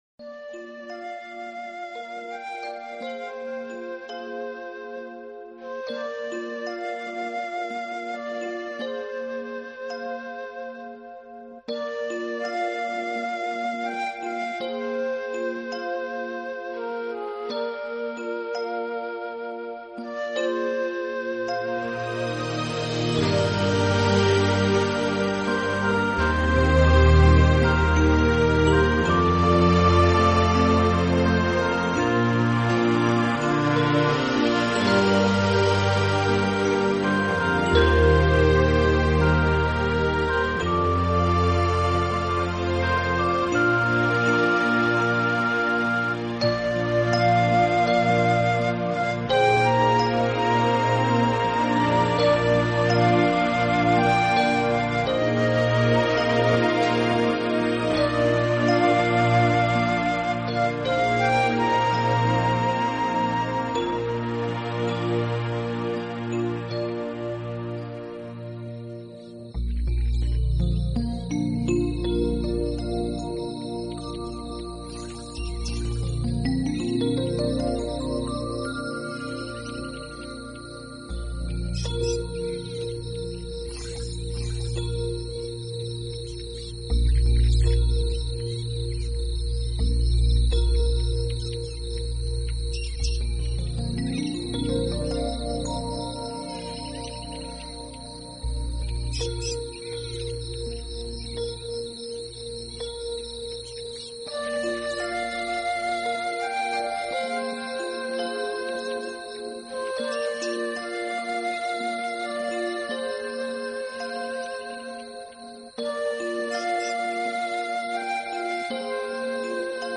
充满了来自雨林的神奇声响，还有一种梦幻舒缓的特质贯